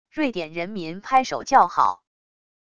瑞典人民拍手叫好wav音频